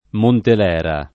Montelera [ montel $ ra ]